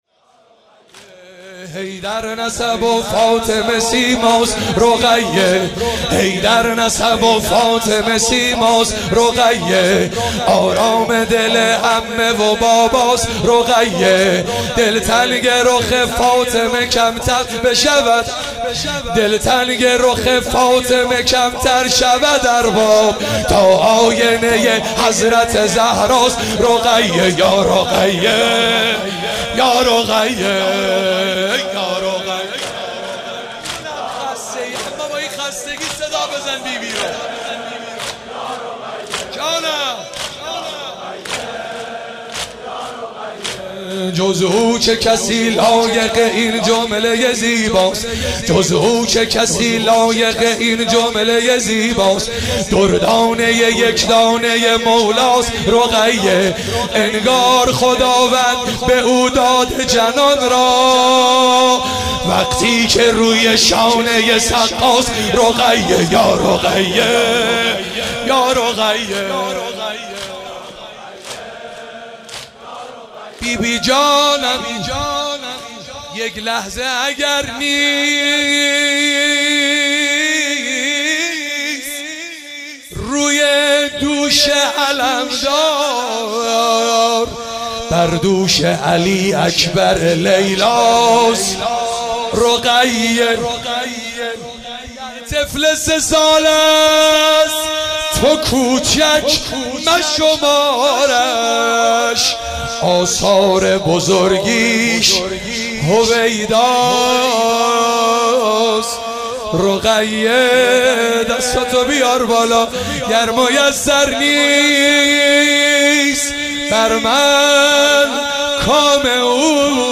مداحی شب سوم محرم 99